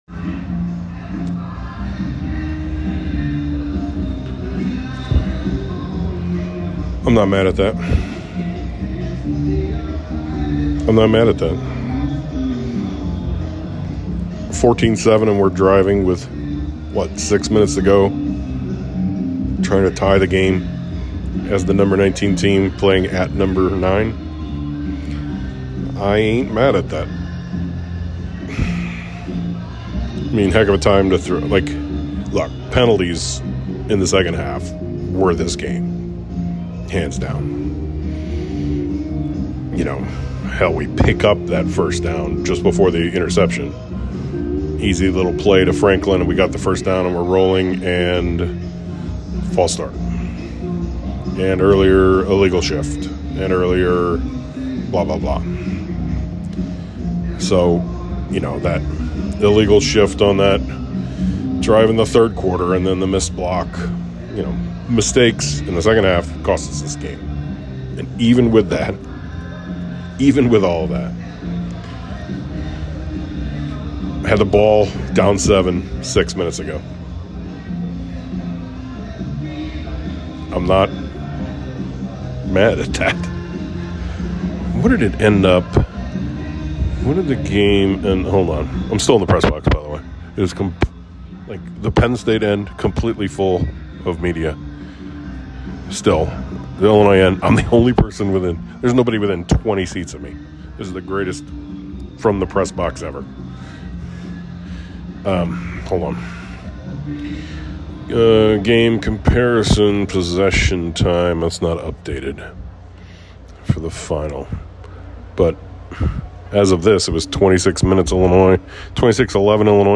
Headliner Embed Embed code See more options Share Facebook X Subscribe I thought I had an empty end of the pressbox so this From The Stands is From The Pressbox.